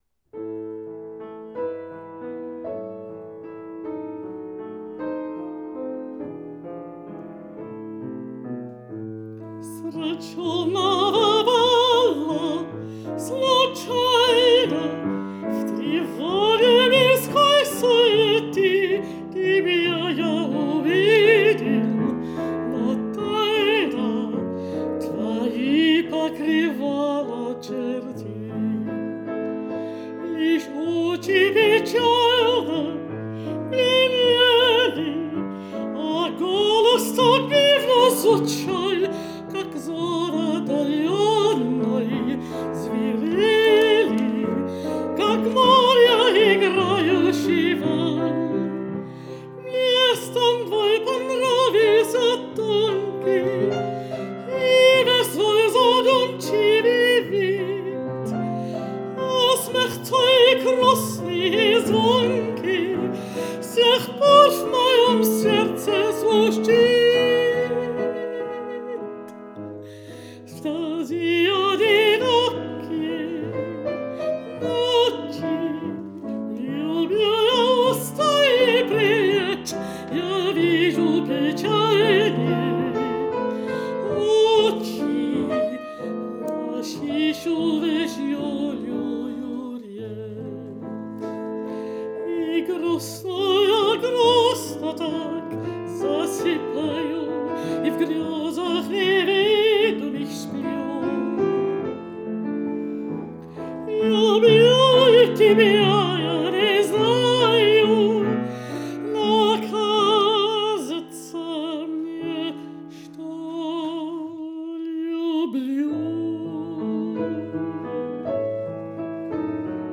Mezzosoprano
Piano